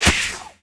attack_2.wav